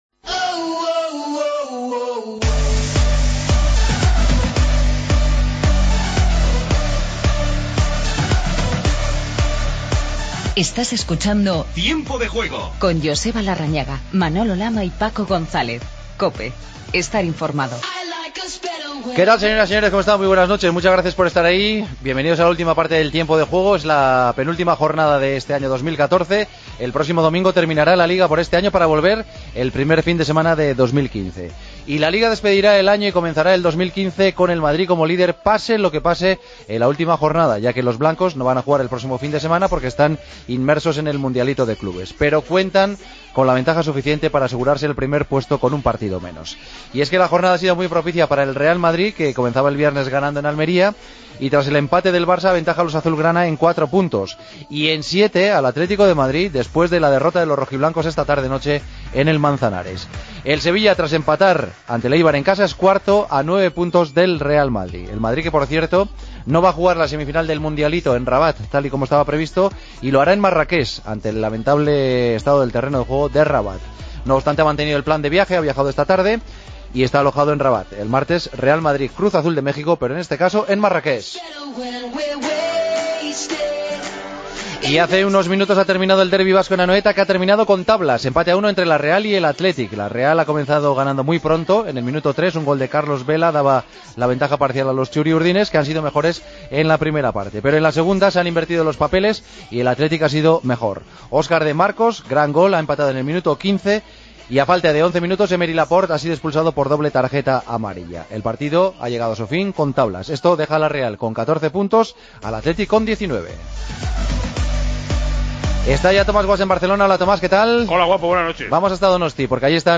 Entrevistas a De Marcos y Bergara. Hablamos de la derrota del Atleti ante el Villarreal. Entrevista a Tiago Mendes.